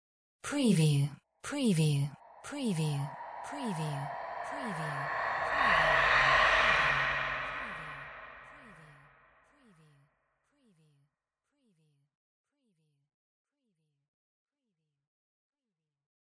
Surreal Whisper FX 026
SCIFI_WHISPERS_SPOOKY_WBSD026
Stereo sound effect - Wav.16 bit/44.1 KHz and Mp3 128 Kbps
previewSCIFI_WHISPERS_SPOOKY_WBHD026.mp3